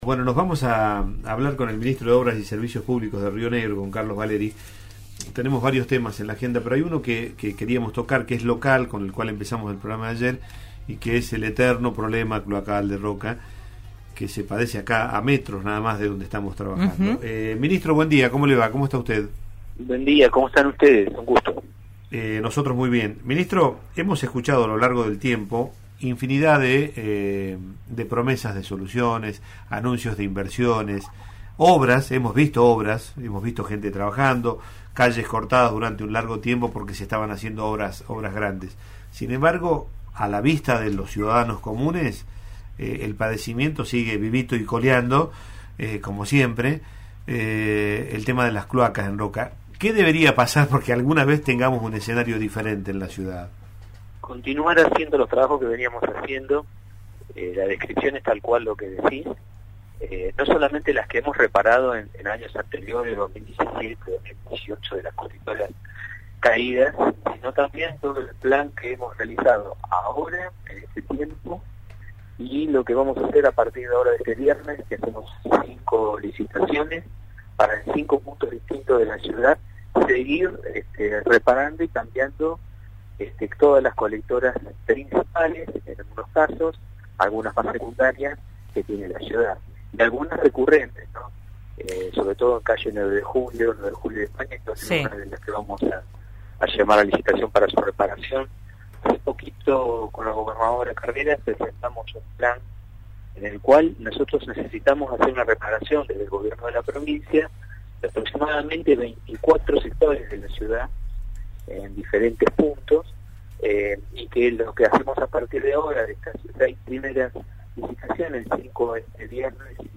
Carlos Valeri, ministro de Obras Públicas conversó esta mañana con RN Radio y aclaró una de las incógnitas que dejó el anuncio de Energía.